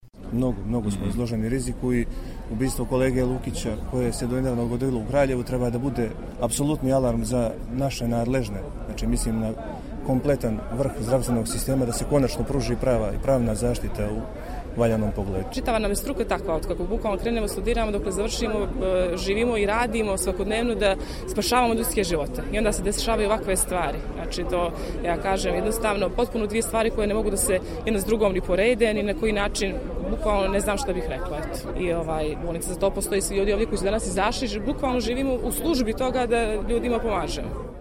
Medicinsko osoblje